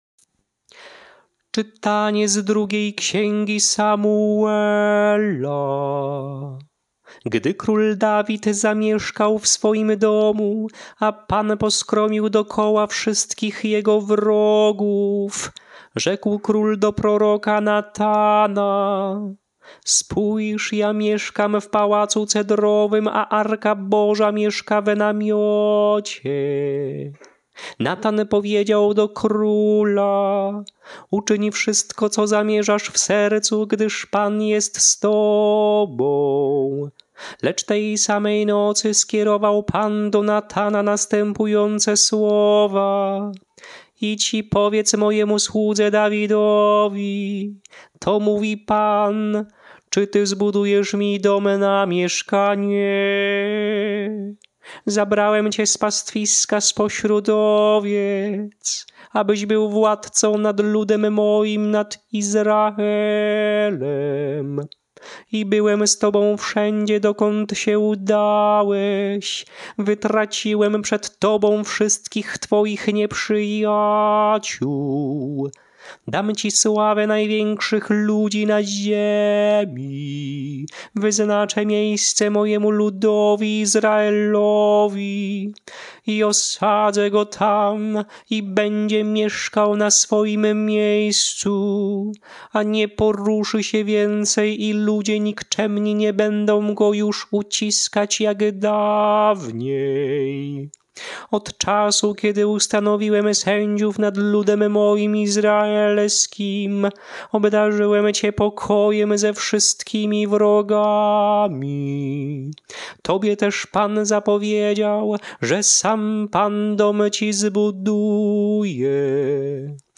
Śpiewane lekcje mszalne – IV Niedziela Adwentu.
Melodie lekcji mszalnych przed Ewangelią na IV Niedzielę Adwentu: